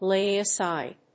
láy asíde
lay+aside.mp3